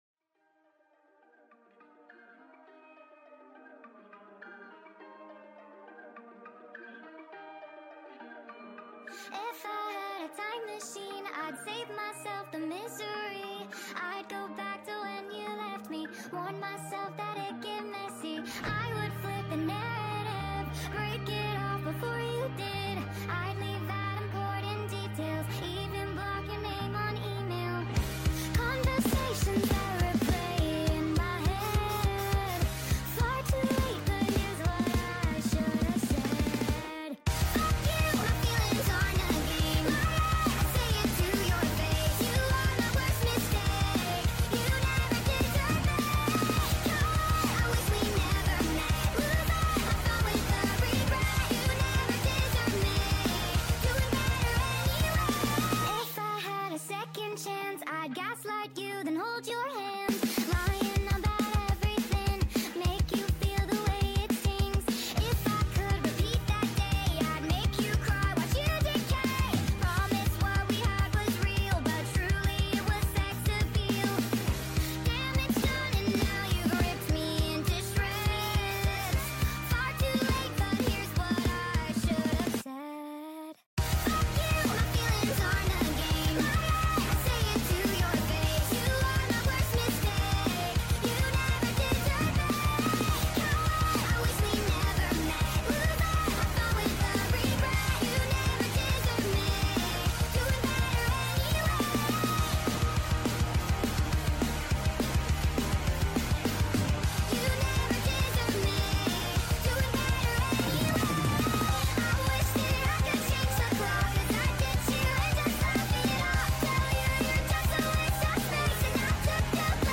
Full Song + Sped Up